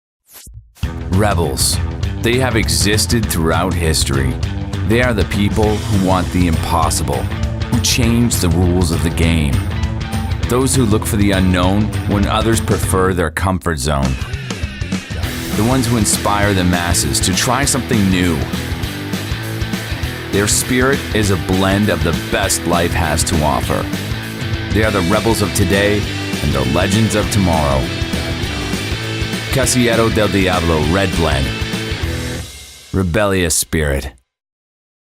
Standard American, New York, California, Canadian West Coast.
Friendly, conversational, guy next door